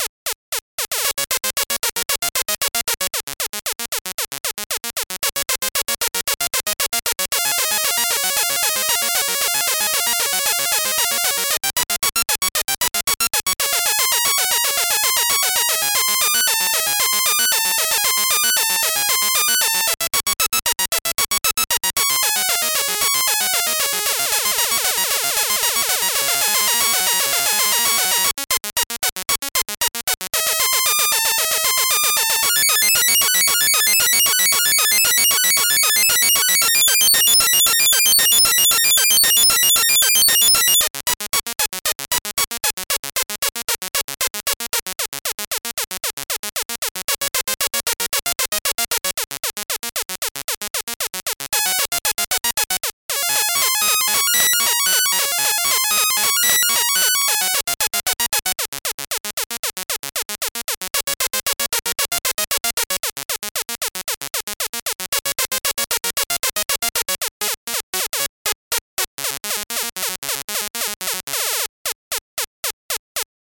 • 1-bit sound generated by CPU
• CPU Z80 3.5MHz, 48Kb RAM
1tracker, huby engine